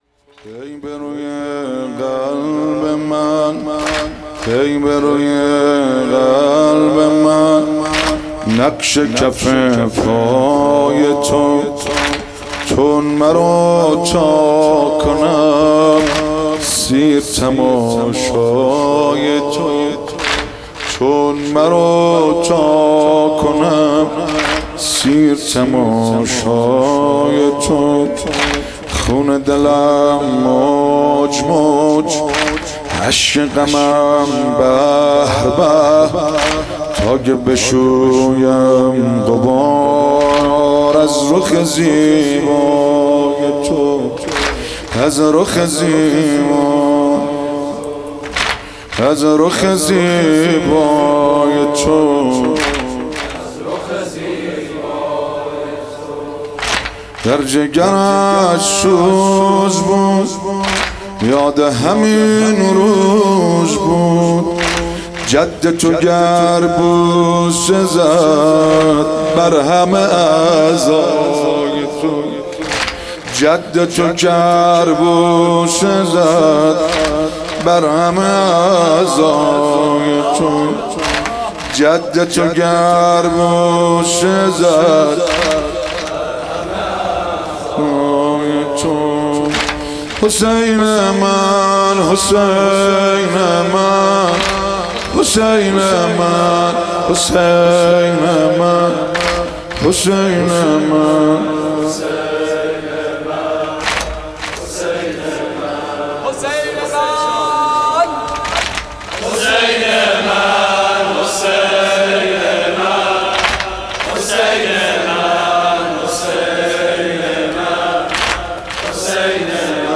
مراسم وفات حضرت سکینه س